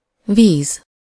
Ääntäminen
IPA: /ˈviːz/